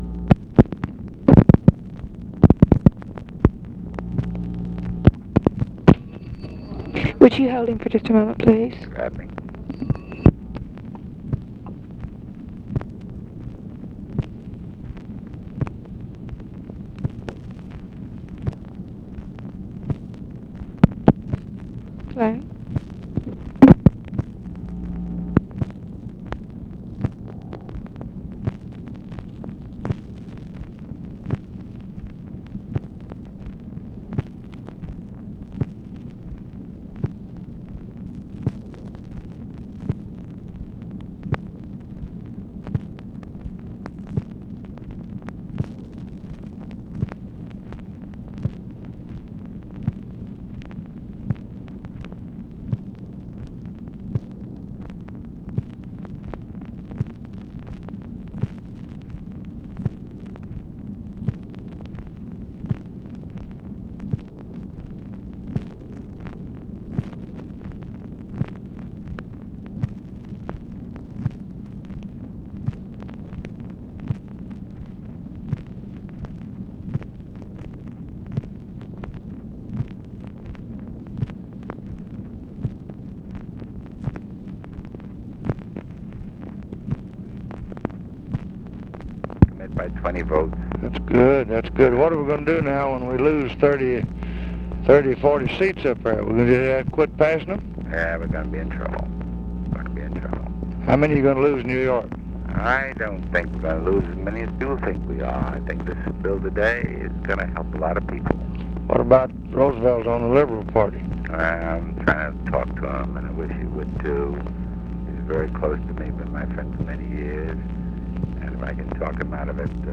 Conversation with ADAM CLAYTON POWELL, September 8, 1966
Secret White House Tapes